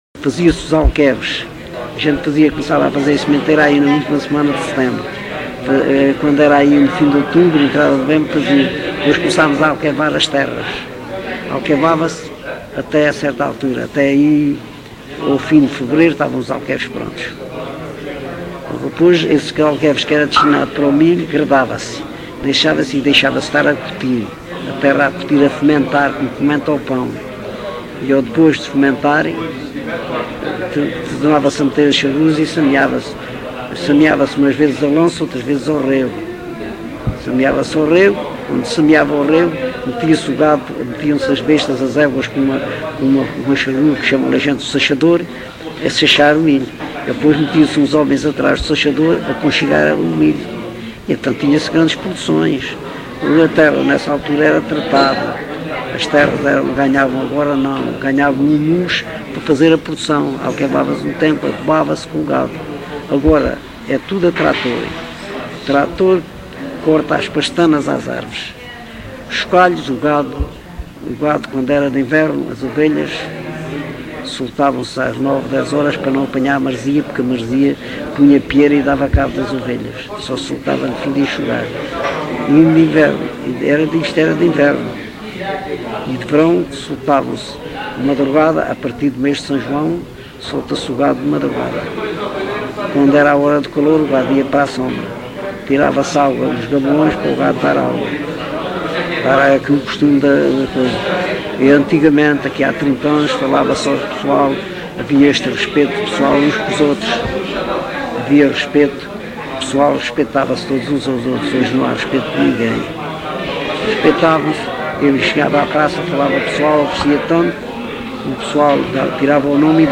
LocalidadeCouço (Coruche, Santarém)